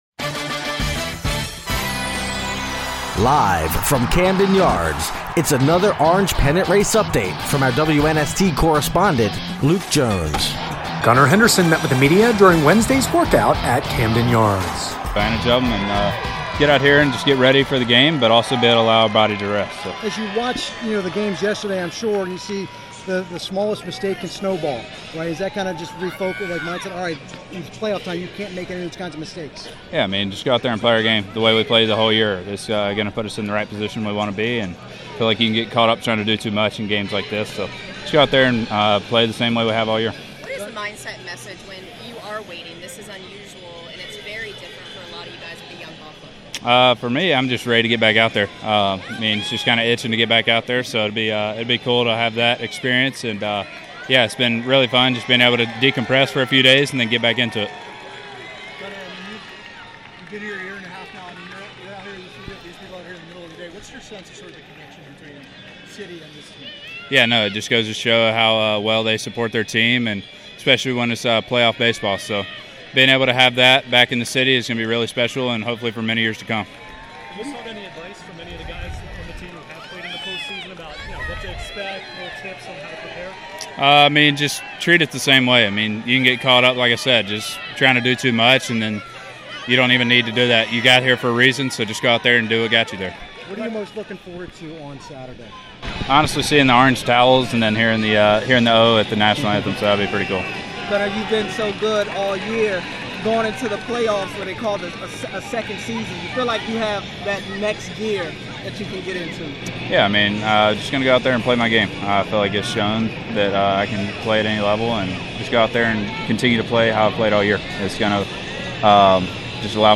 Gunnar Henderson talks with reporters during Wednesday workout at Camden Yards
Locker Room Sound